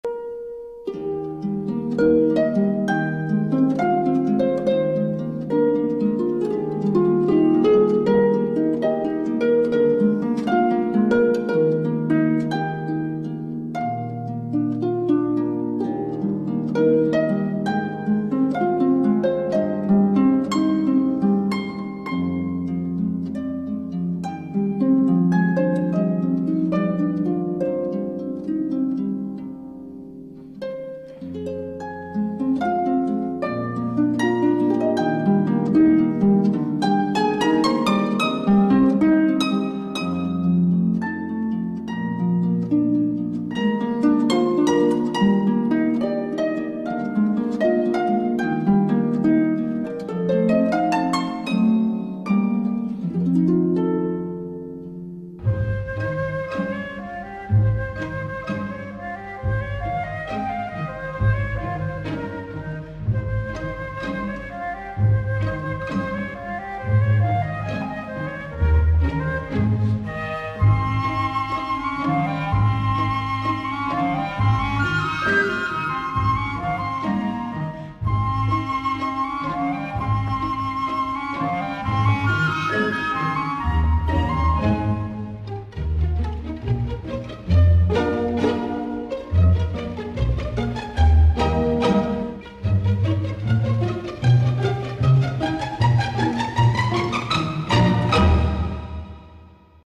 с инструментальным звуком